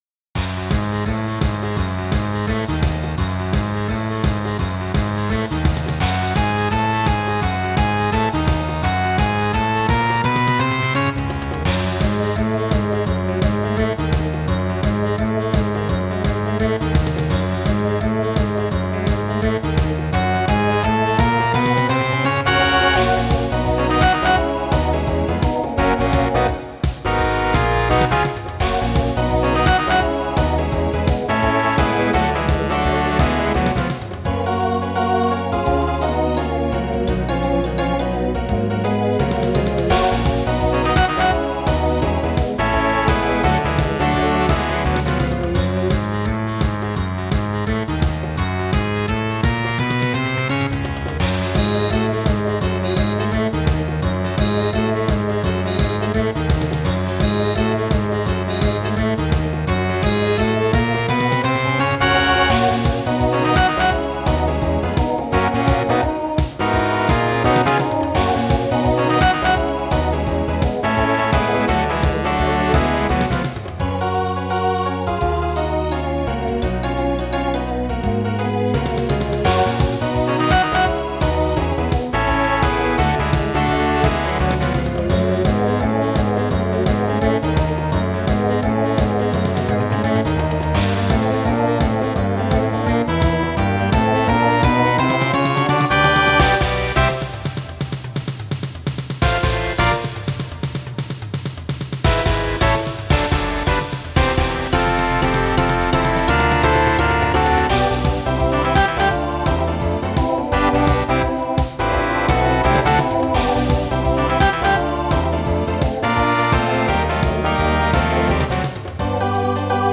Voicing TTB Instrumental combo Genre Broadway/Film